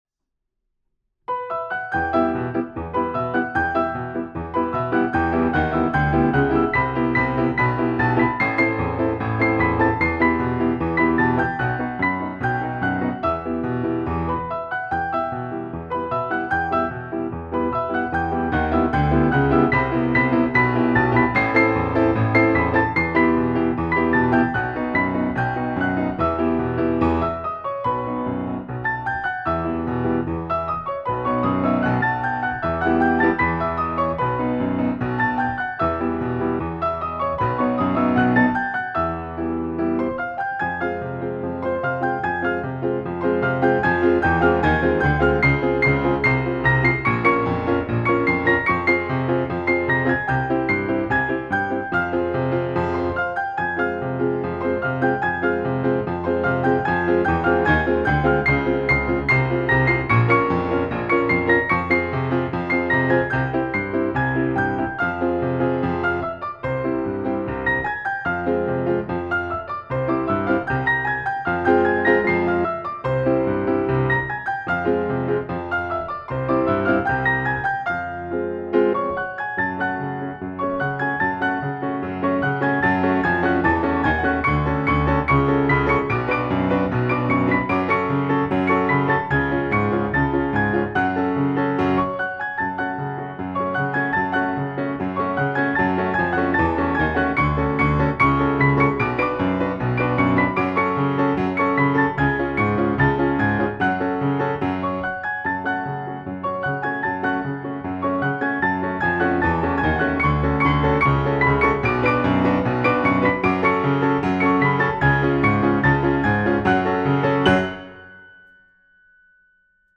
Duets, Quatre Mains
Duet
Tunes are recorded in our studio.